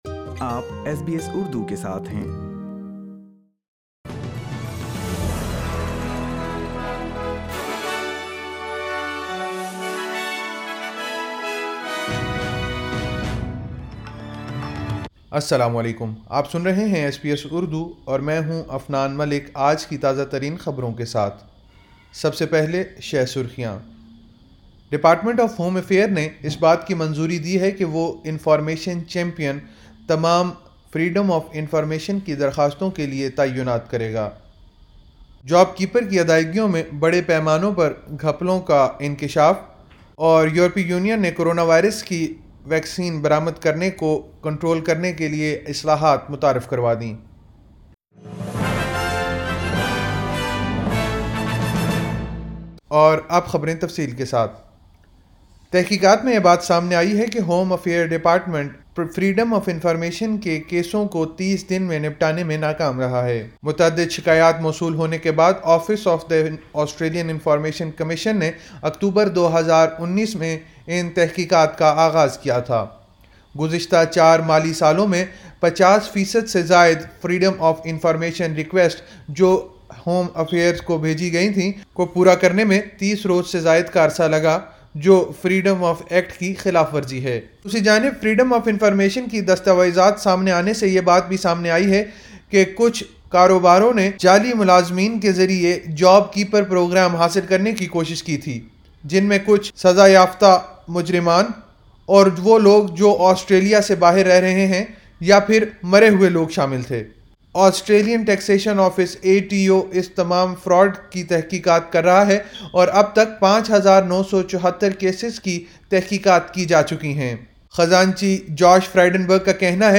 ایس بی ایس اردو خبریں 30 جنوری 2021